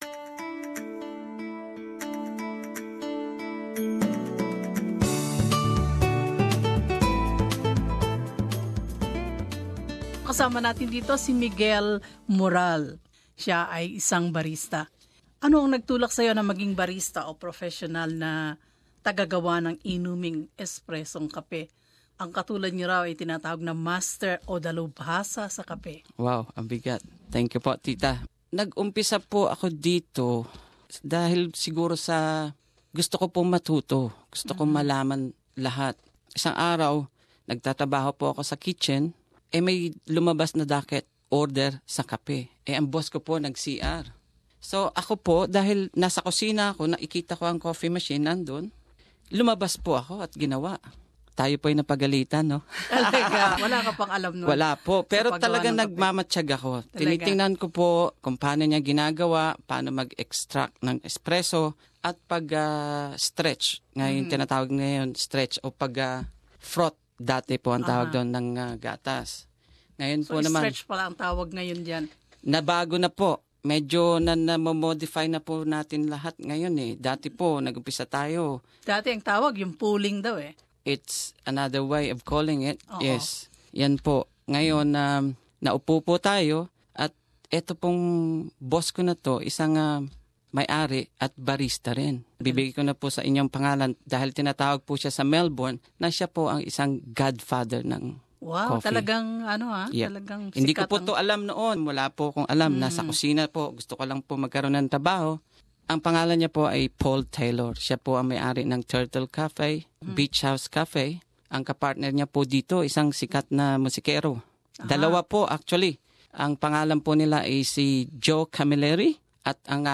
In this interview he tells us how his passion for coffee making evolved through many years of experience behind the coffee machine.